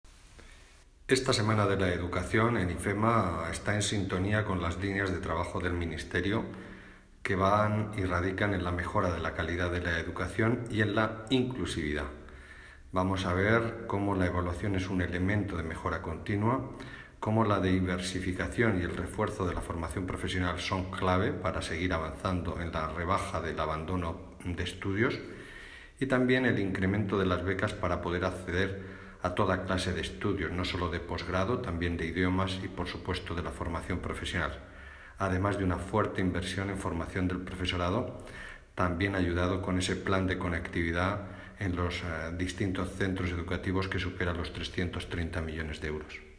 Declaraciones del secretario de Estado de Educación, Formación Profesional y Universidades, Marcial Marín, tras su visita al Salón Internacional del Estudiante y de la Oferta Educativa, AULA 2016.